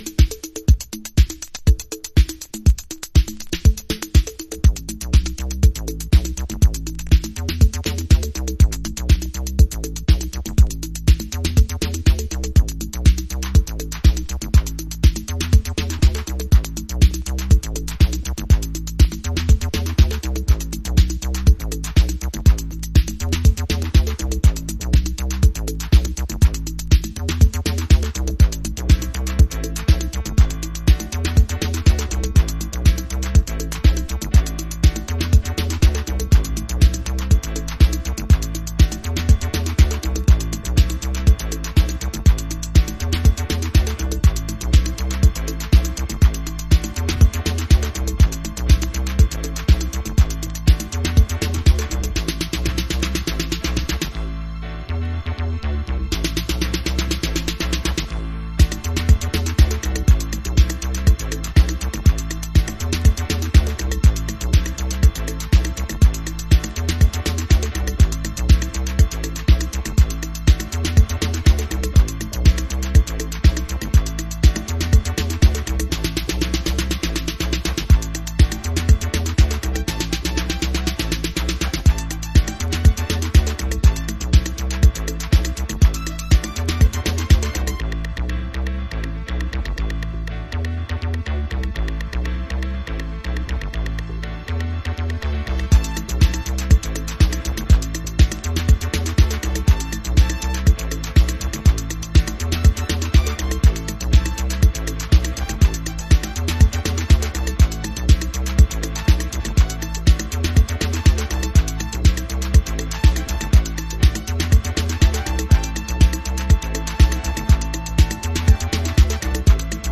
Chicago Oldschool / CDH
まだまだ、イタロや80'sディスコの影響が垣間見られるトラックス。